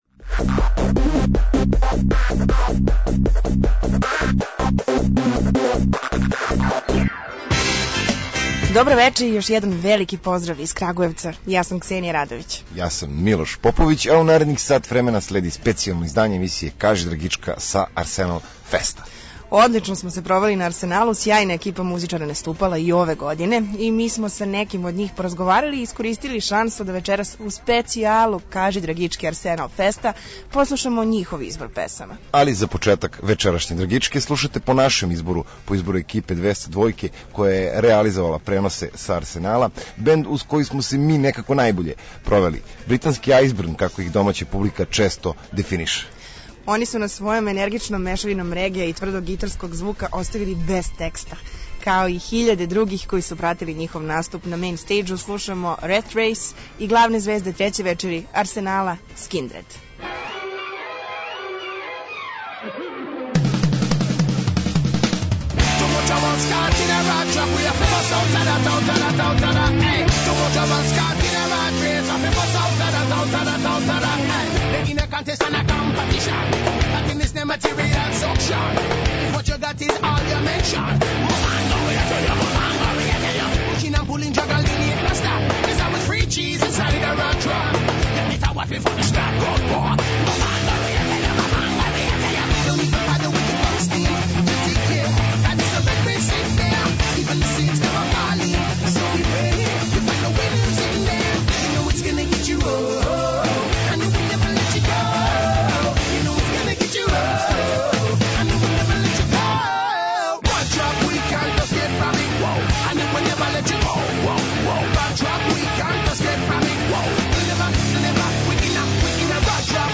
Специјална емисија са Арсенал феста